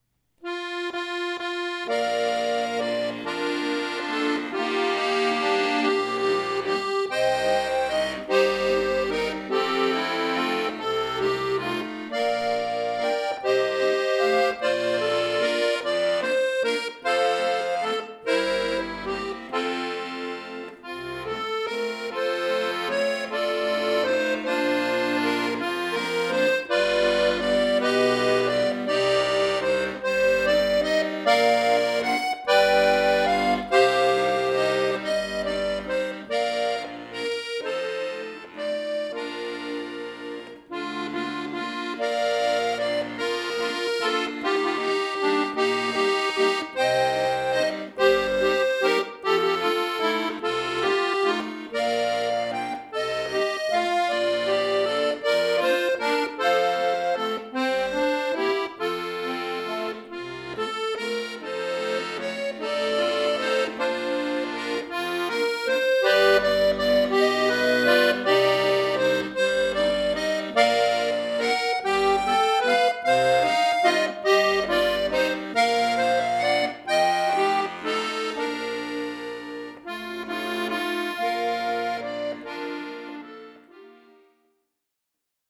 Berühmter Walzer
für Akkordeon solo
Klassisch, Walzer